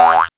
VEC3 Percussion 021.wav